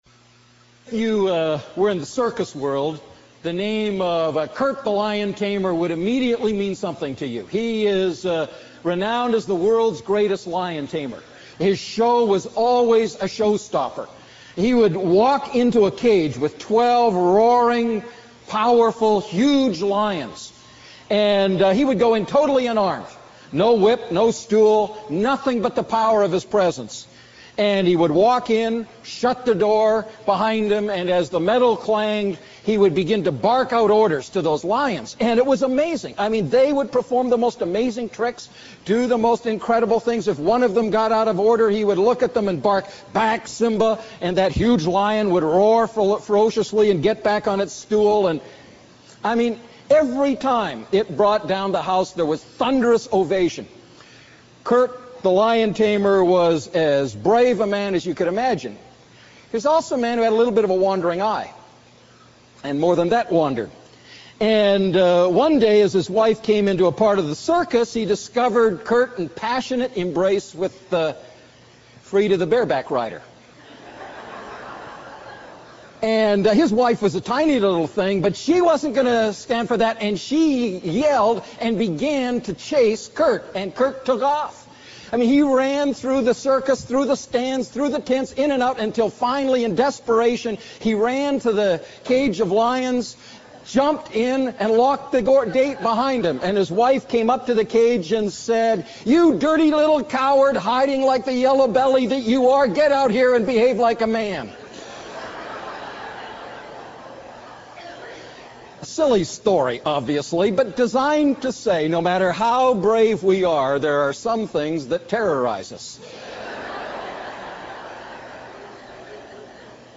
A message from the series "Living Inside Out."